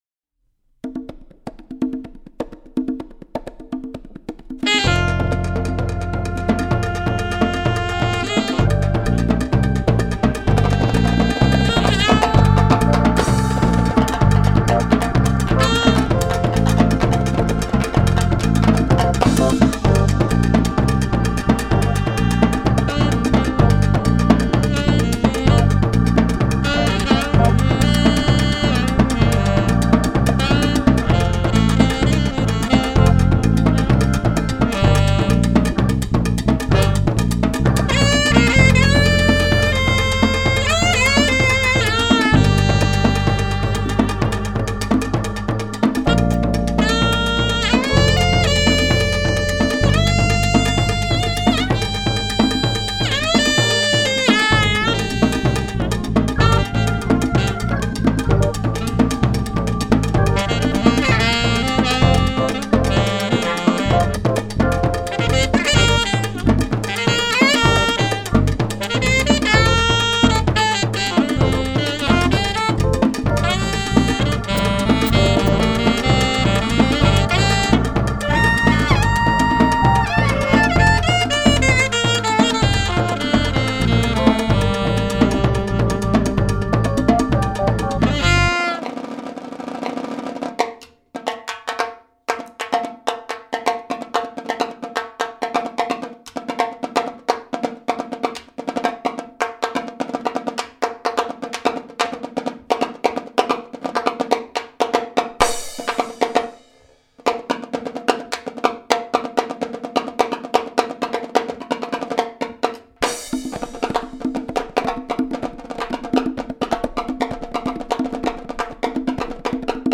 Bass, Hammond B3 Organ, Leslie Piano
Timbales, Congas
Saxaphone